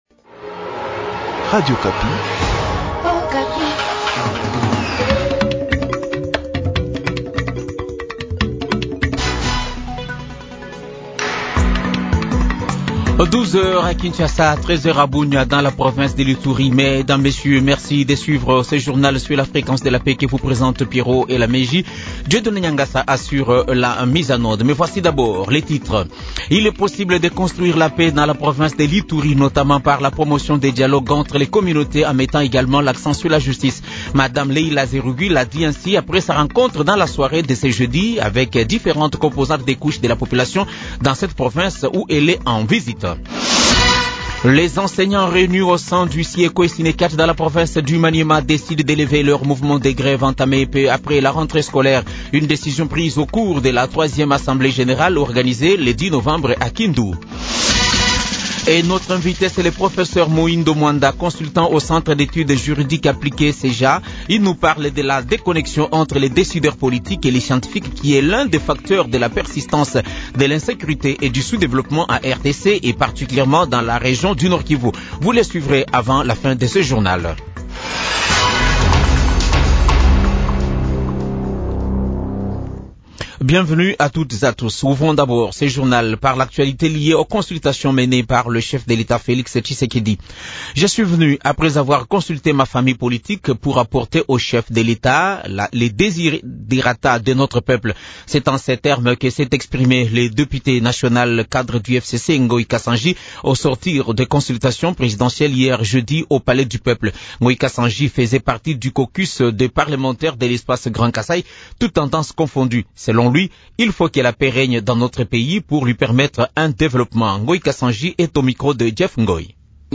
Journal Français Midi